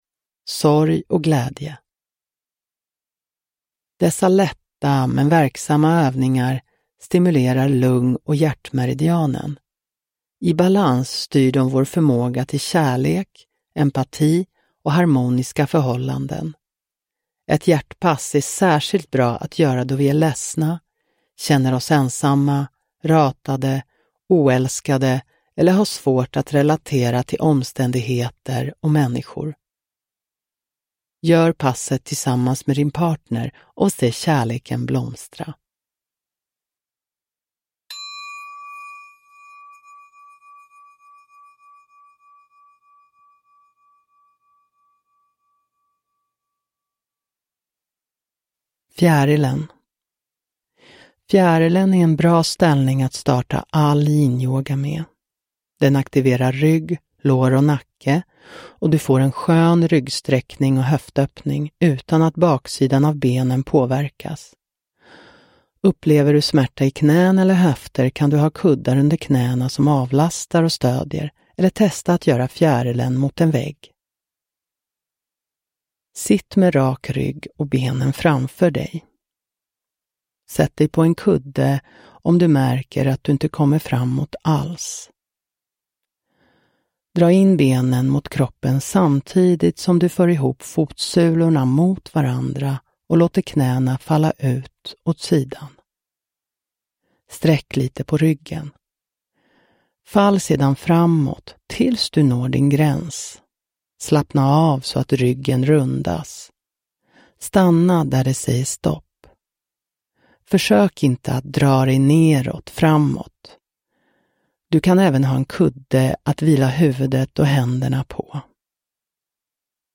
Sorg och glädje – Ljudbok – Laddas ner